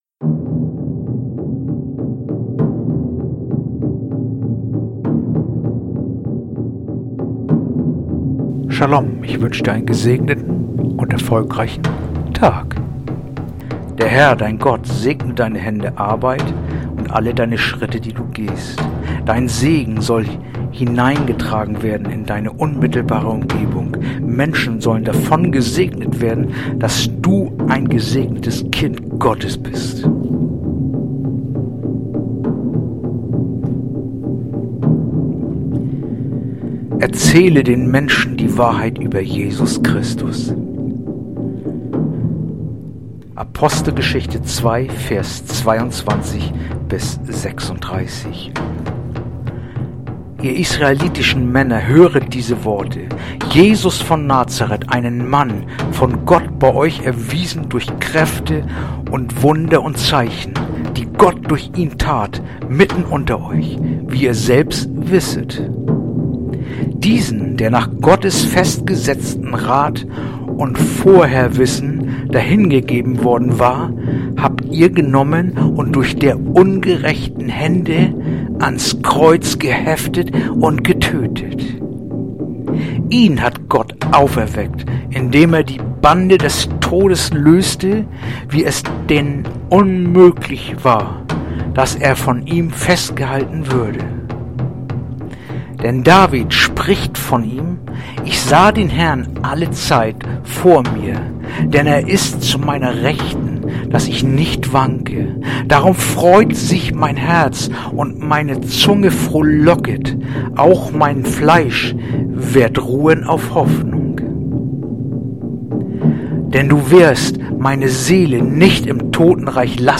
heutige akustische Andacht
Andacht-vom-15.-August-Jeremia-336.mp3